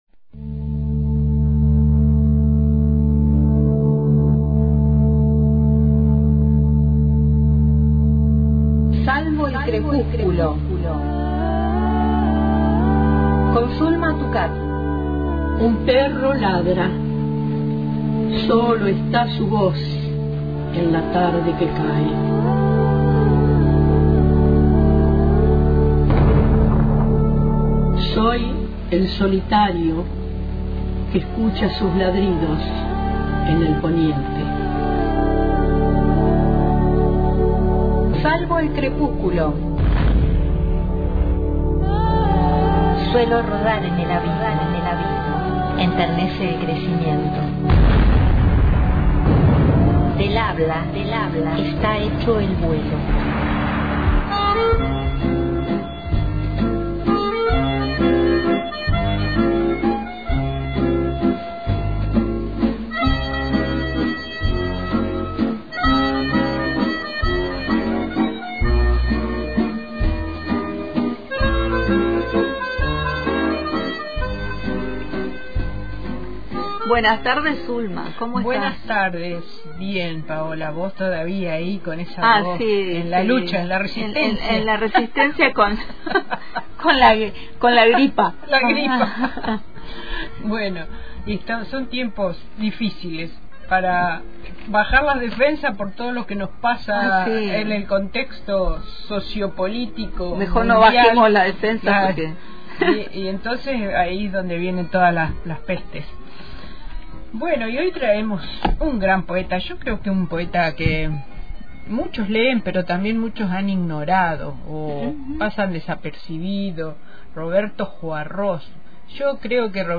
Sin fechas ni localismos, cada texto apunta a “desfosilizar” el lenguaje y a romper la ley de gravedad que nos arrastra hacia abajo. En una entrevista recuperada, el autor define esa fuerza ascendente como el impulso a pensar, amar y escribir.